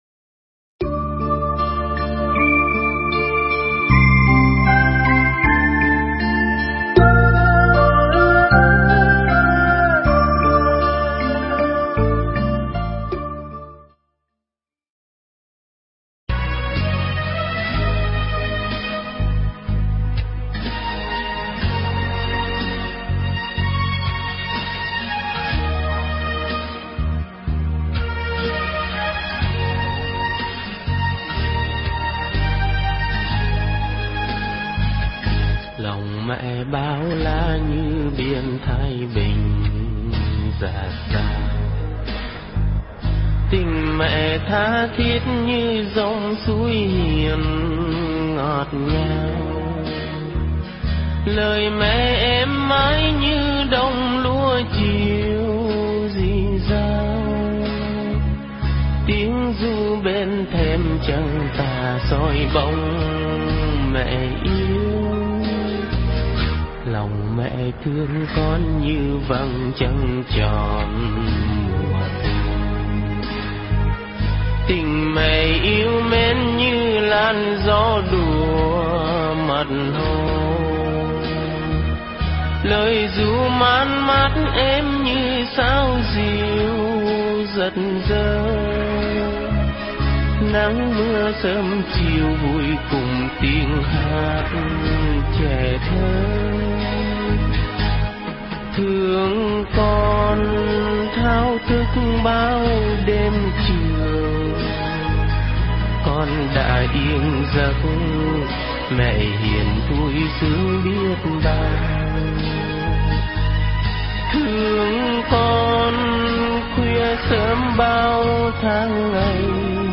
Nghe Mp3 thuyết pháp Tấm Lòng Mẹ
Nghe mp3 pháp thoại Tấm Lòng Mẹ